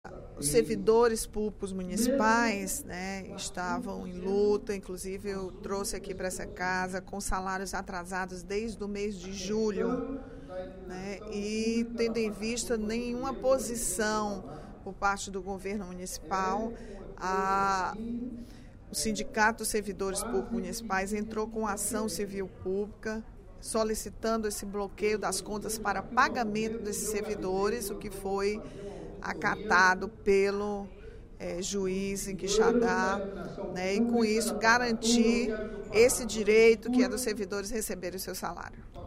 A deputada Rachel Marques (PT) anunciou, no segundo expediente da sessão plenária da Assembleia Legislativa desta quarta-feira (23/09), que o juiz Saulo Gonçalves Santos oficiou, na última sexta-feira, às instituições financeiras, para que procedam o imediato bloqueio das contas da Prefeitura de Quixadá. A medida, de acordo com a parlamentar, visa a assegurar o pagamento do salário dos servidores municipais, que não receberam ainda o salário de julho.